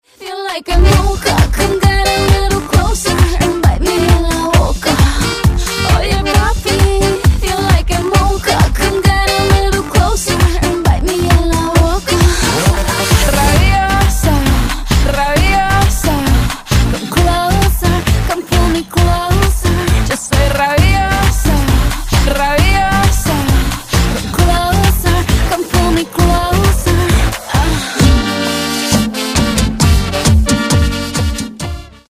sonerie mp3 house